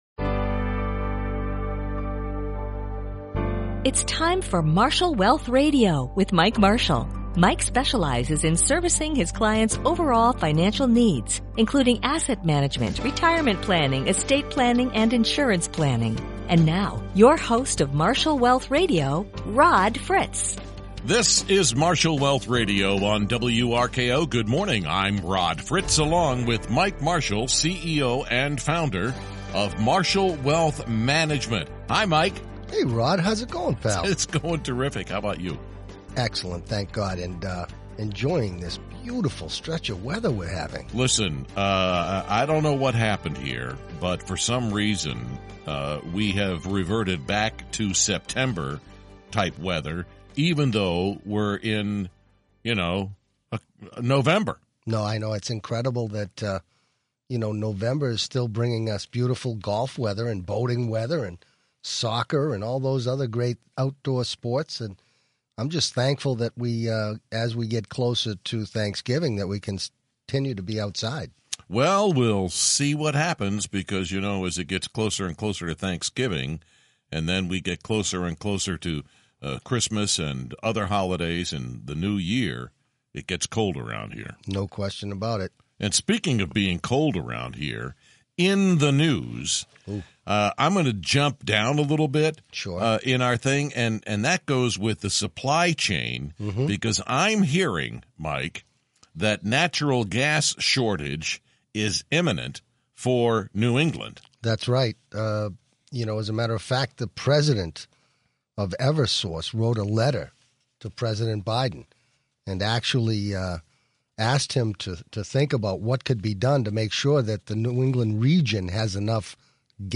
Their show simplifies complex financial topics, making retirement planning and wealth management both understandable and engaging. With fun, relatable discussions, they provide realistic advice tailored to help you achieve your retirement goals.